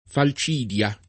fal©&dLa] s. f. — es.: far la falcidia, cioè levarne la quarta parte, tratto dalla legge di Falcidio tribuno della plebe [